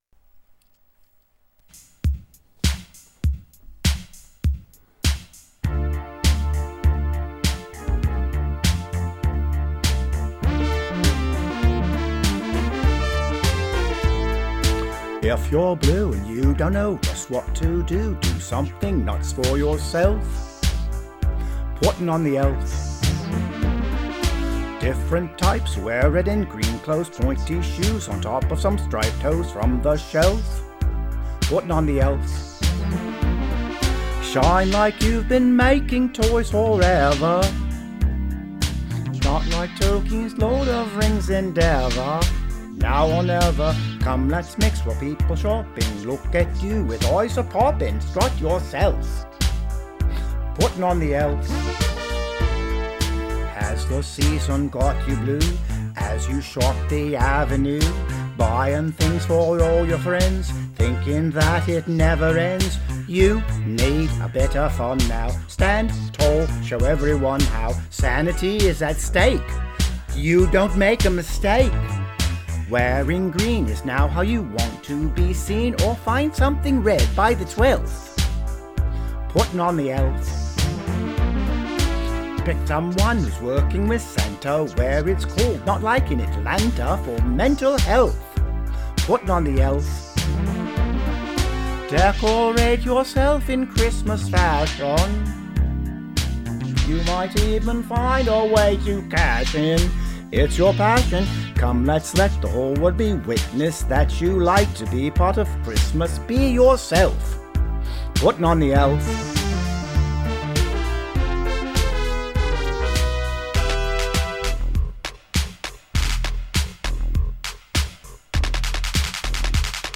If you want to hear me read And sing this Press Play If not read on